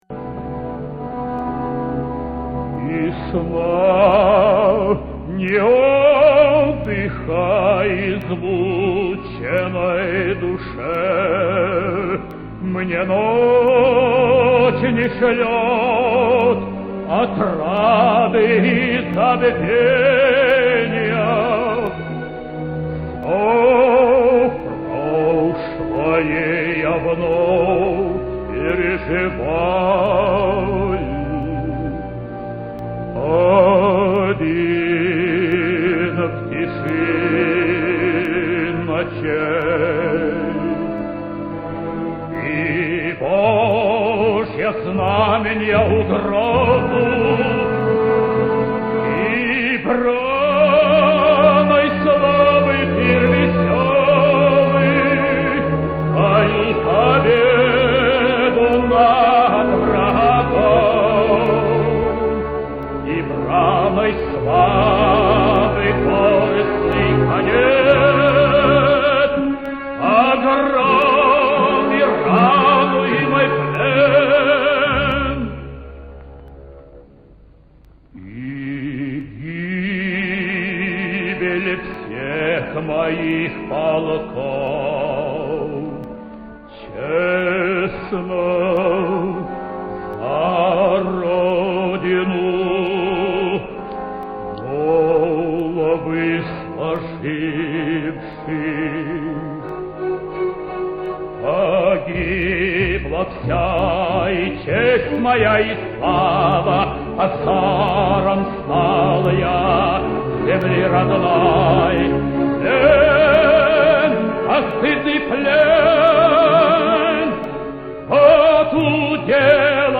dmitriy-gnatyuk---ariya-igorya-(a.p.borodin.-knyaz-igor).mp3